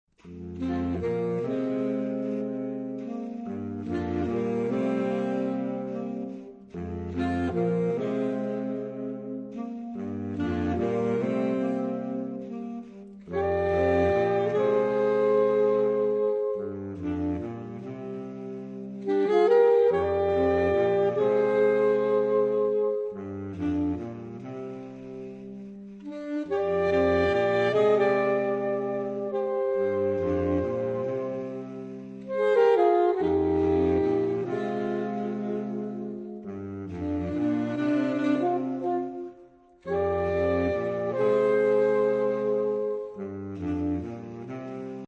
Obsazení: 4 Saxophone (SATBar/AATBar)